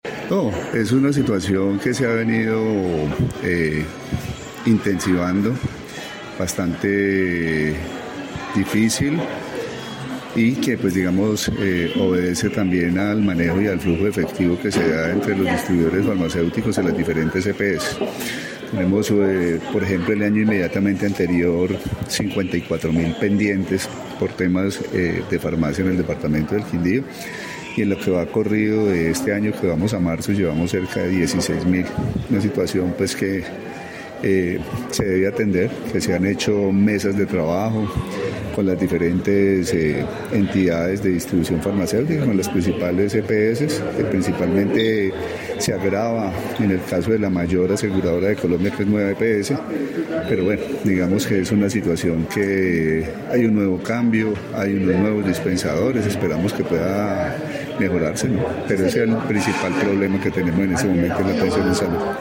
Secretario de Salud del Quindío